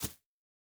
Bare Step Grass Hard C.wav